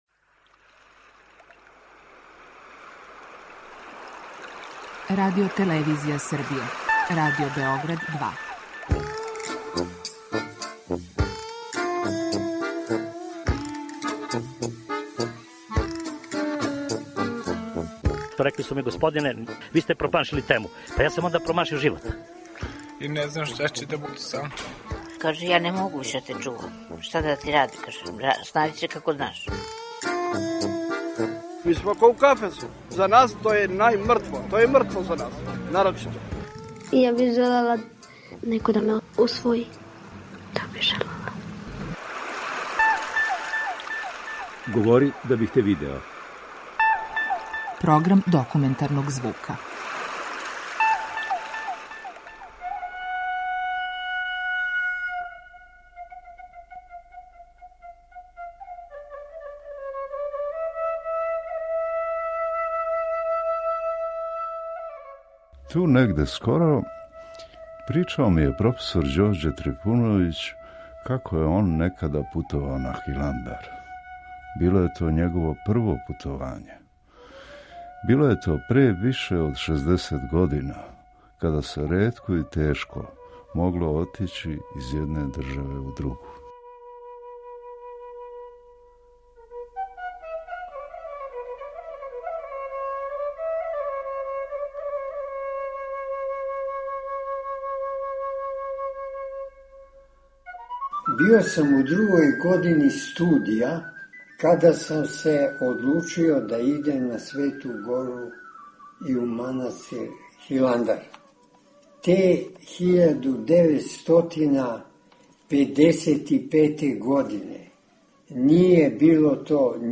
Документарни програм
Серија полусатних документарних репортажа